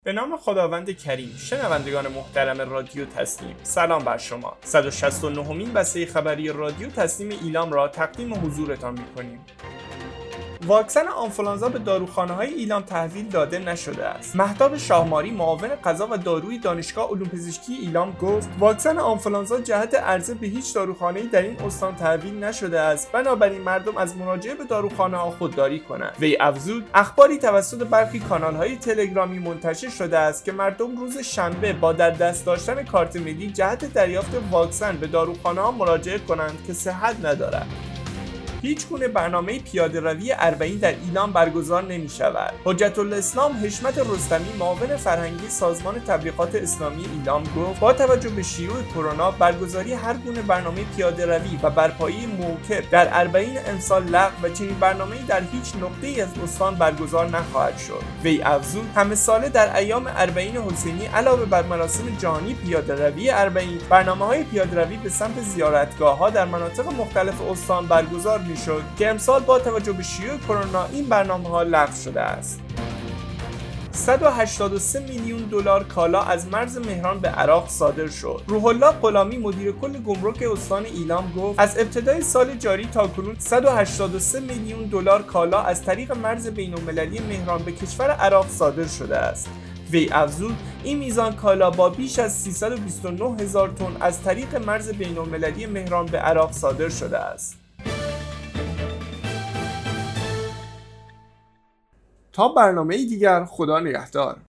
به گزارش خبرگزاری تسنیم از ایلام، صد و شصت و نهمین بسته خبری رادیو تسنیم استان ایلام باخبرهایی چون واکسن آنفلوانزا به داروخانه‌های ایلام تحویل نشده است، هیچگونه برنامه پیاده ‌روی اربعین در ایلام برگزار نمی‌شودو 183 میلیون دلار کالا از مرز مهران به عراق صادر شد، منتشر شد.